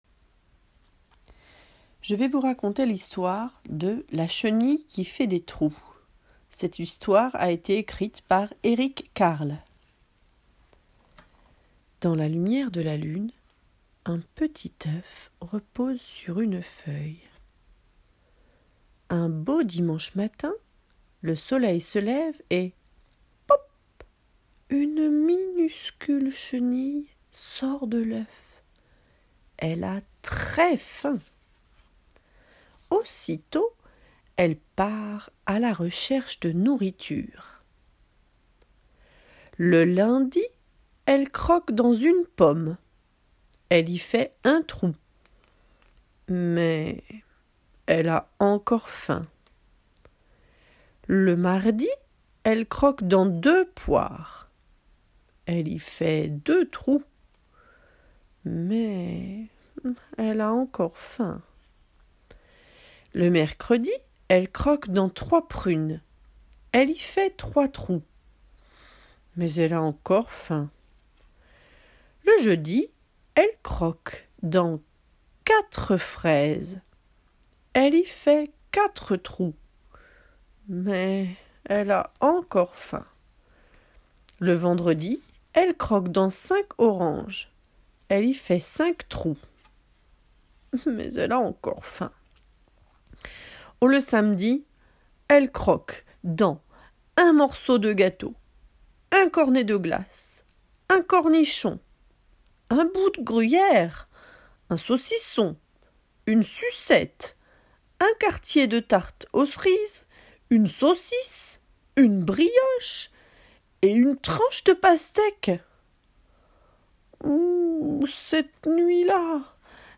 histoire à écouter – la chenille qui fait des trous